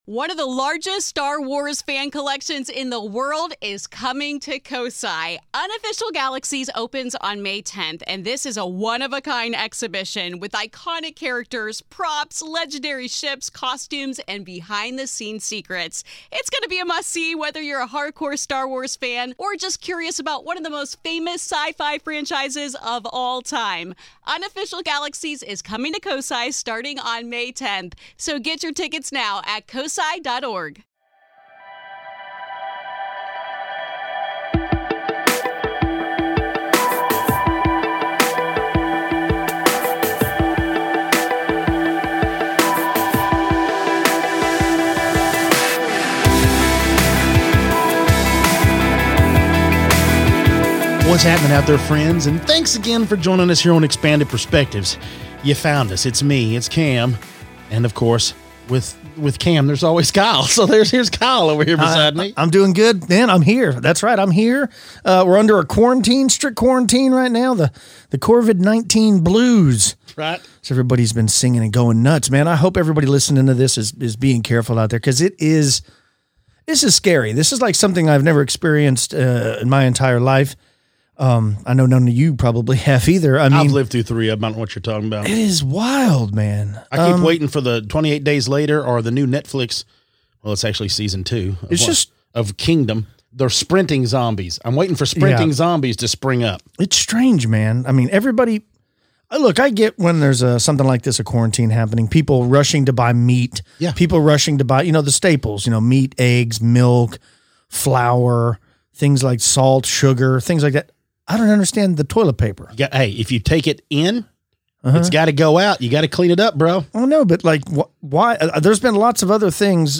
On this episode of Expanded Perspectives the guys start the show off talking about the recent quarantine, the COVID-19 virus and the lack of toilet paper. Then, some sightings of gnomes, fey folk and a possible alien abduction.